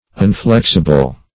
Unflexible \Un*flex"i*ble\, a.